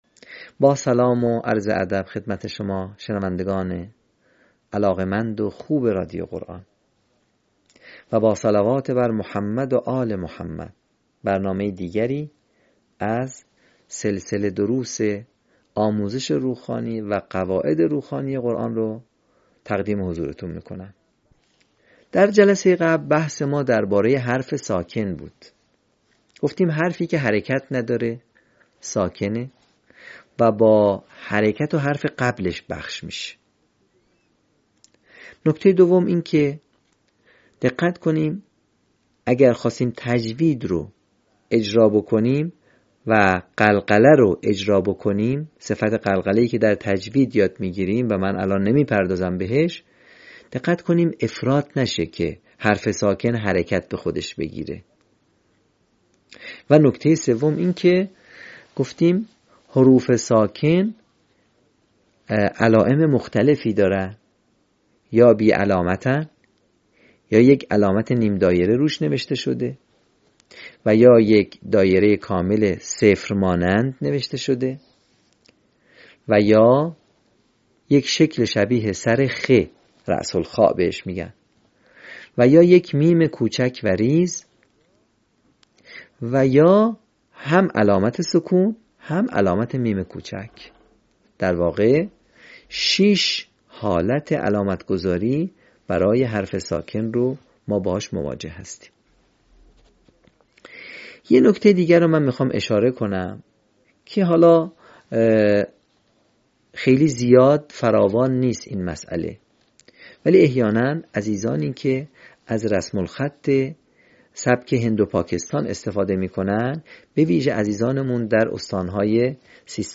صوت | آموزش روخوانی «واو و یای مدی»